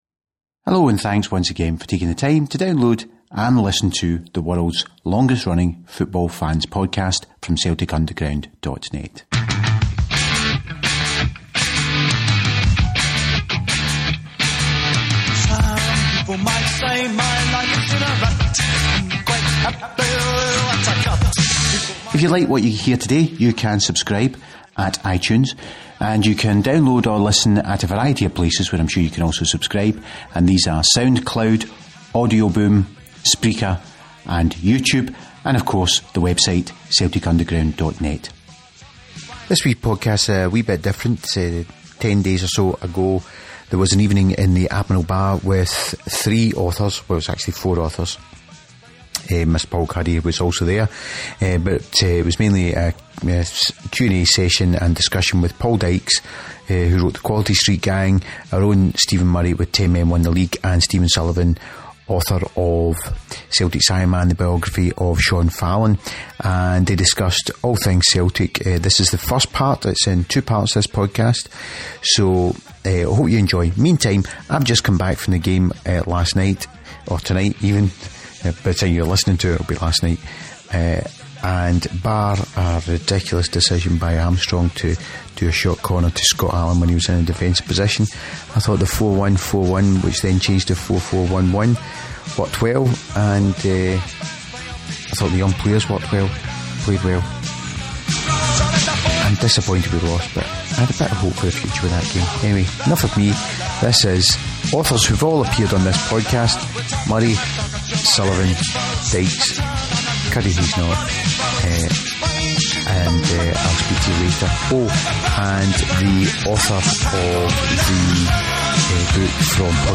This is the first part of audio from the evening with part two up over the weekend.